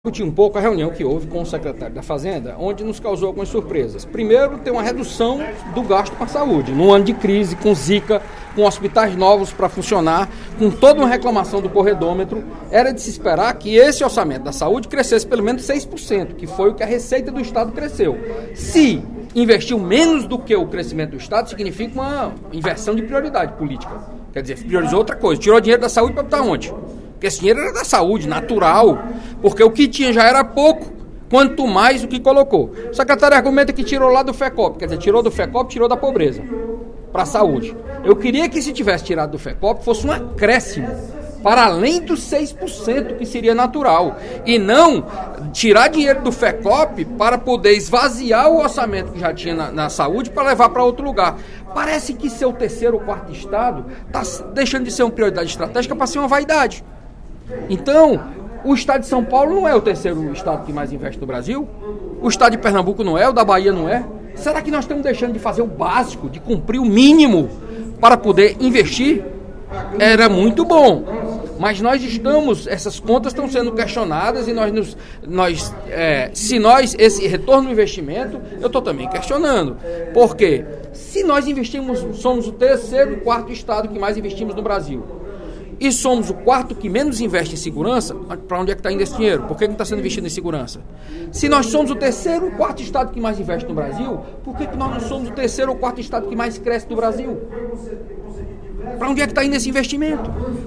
O parlamentar se pronunciou no primeiro expediente da sessão plenária desta quarta-feira (02/03).
Dep. Carlos Matos (PSDB) Agência de Notícias da ALCE